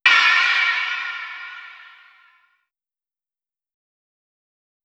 Crash Groovin 2.wav